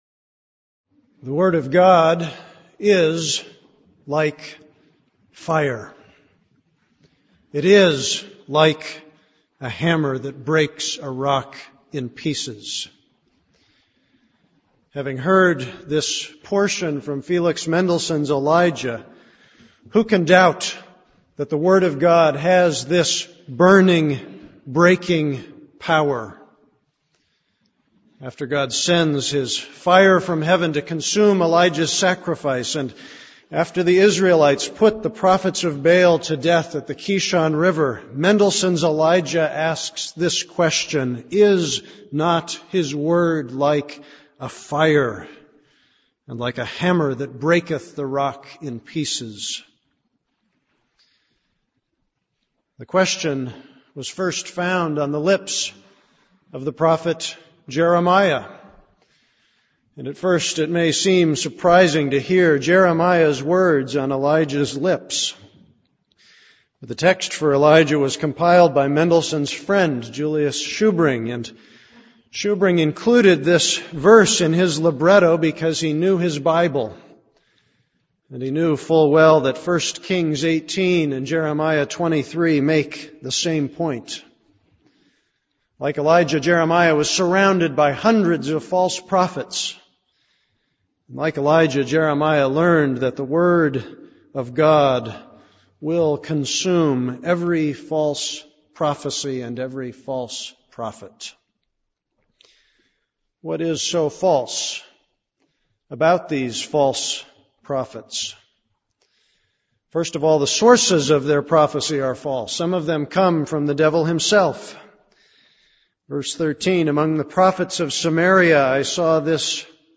This is a sermon on Jeremiah 23:9-40.